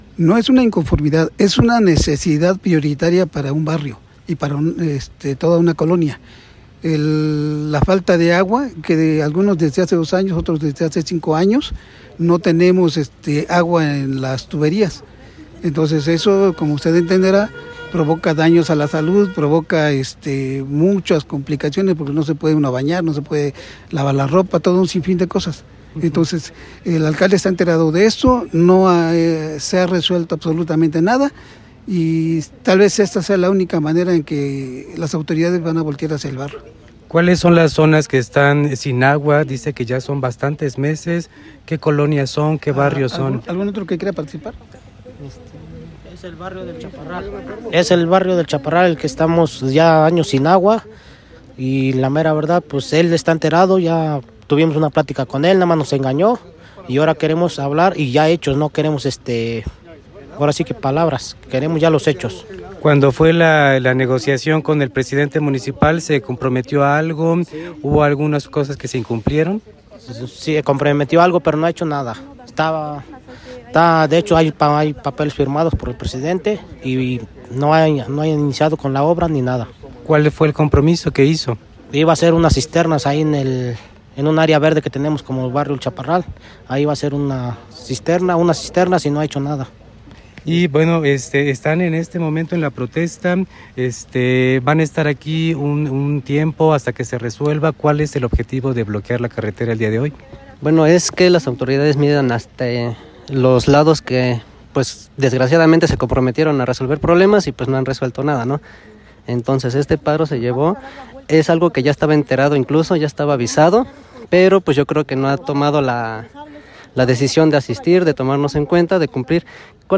Estas son algunas de las palabras de las manifestantes.
vecinos-manifestantes.mp3